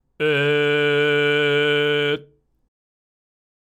「通常の喉頭位置＆グーの声(ん)」の時よりも、口と喉の中に太い筒を通したような広い空間を保った状態で「え」と出してください。
音色のイメージは、自分の身長がプラス10ｍぐらい大きくなった巨人のような深くて野太い声になっていればOKです。
※喉頭を下げたグーの声(え)
07_koutou_G_e_kyojin.mp3